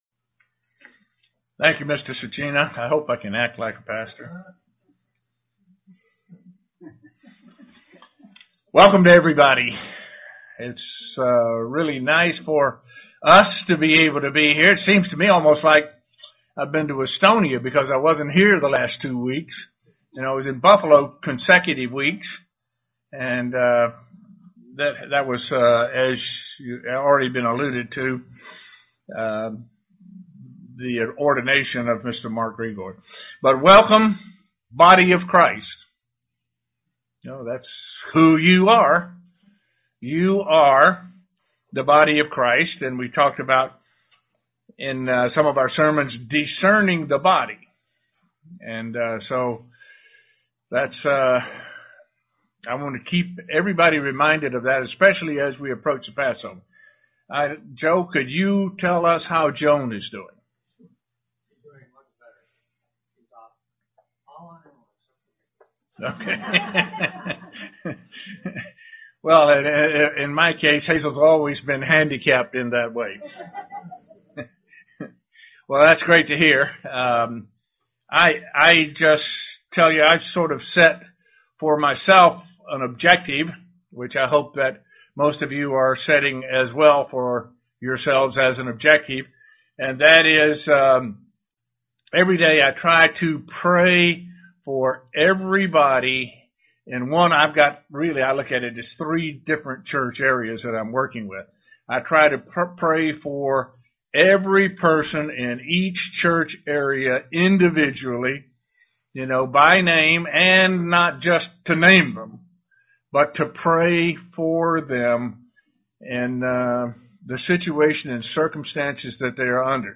Given in Elmira, NY
Print What it means to be accounted worthy and what we should strive to be worthy of UCG Sermon Studying the bible?